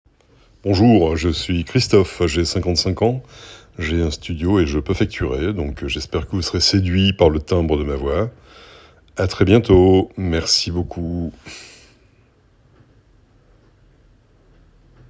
Voix
Voix off
35 - 100 ans - Baryton-basse